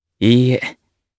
Lucias-Vox-Deny_jp.wav